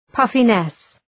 Προφορά
{‘pʌfınıs}